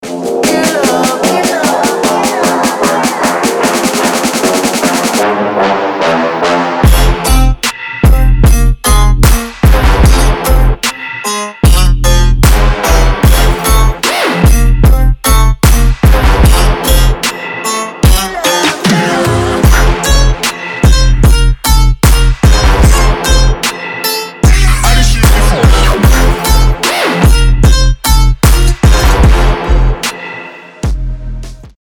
• Качество: 320, Stereo
мужской голос
ритмичные
громкие
dance
Electronic
EDM
Bass
Отрывок трека американских музыкантов.